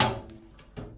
locker_open.ogg